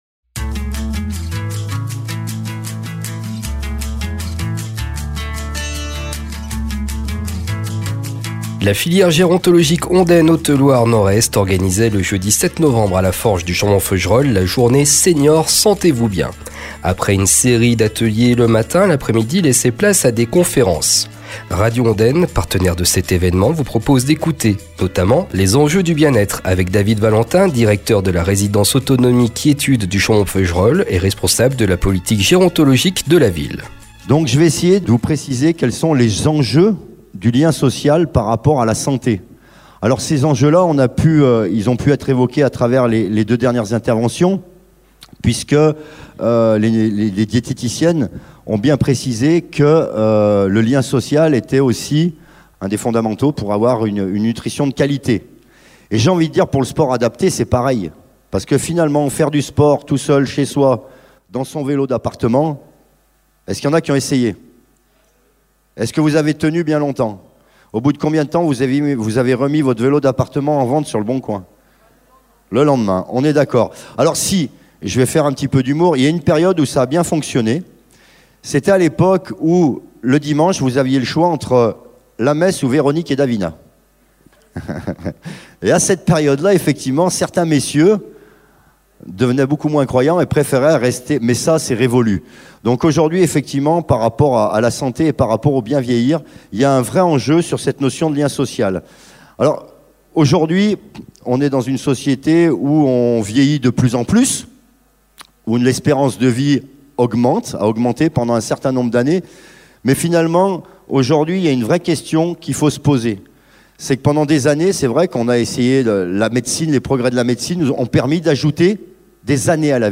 La Filière Gérontologique Ondaine Haute Loire Nord-Est organisait le jeudi 07 Novembre à la Forge du Chambon Feugerolles, la journée » Seniors, Santé-vous bien! ». Après une série d’ateliers en ouverture, l’après-midi laissait place à des conférence. Radio Ondaine, partenaire de l’évènement, a réalisé des captations de ces discussions